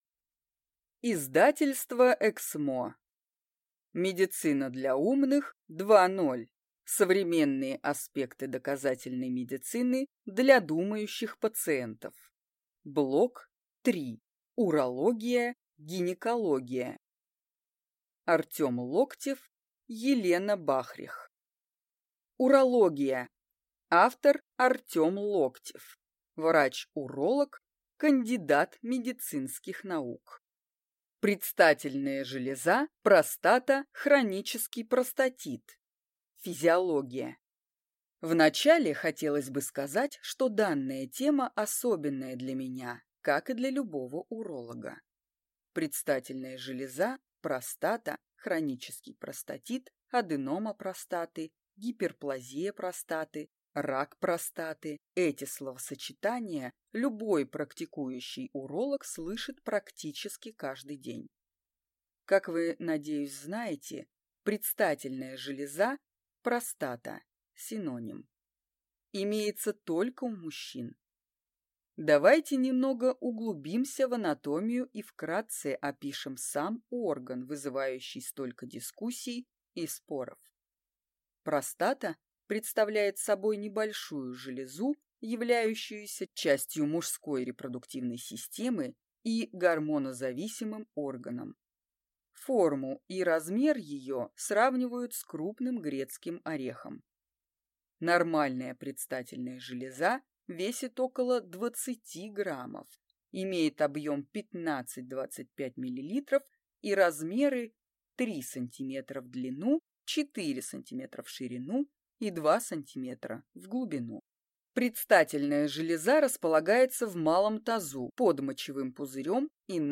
Аудиокнига Медицина для умных 2.0. Блок 3: Урология и гинекология | Библиотека аудиокниг